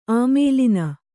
♪ āmēlina